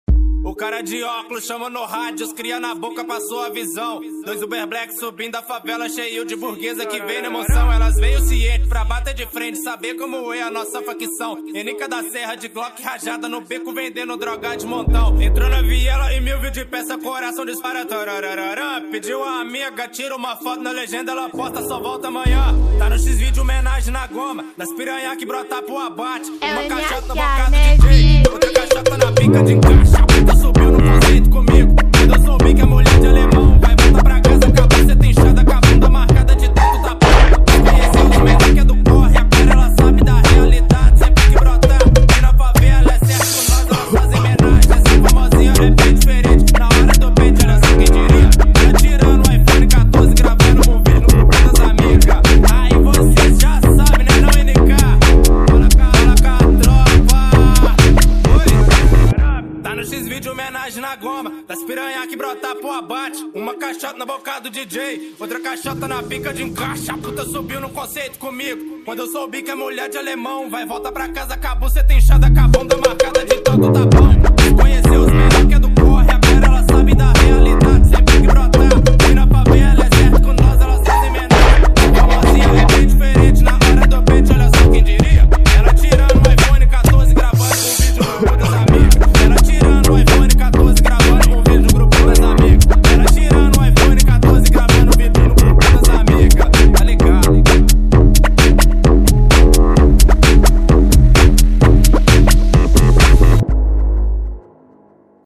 ELETROFUNK 2024
Gênero: Funk